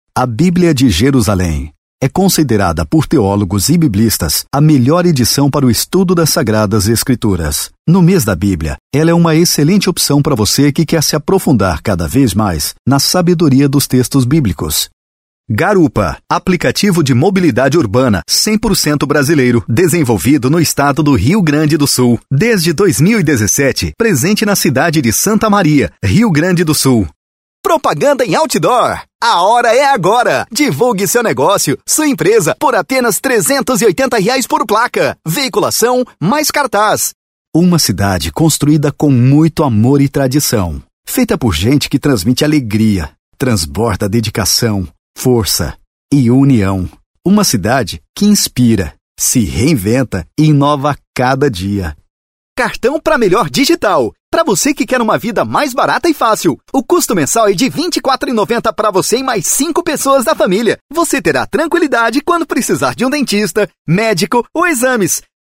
VOZES MASCULINAS
Estilos: Padrão Motivacional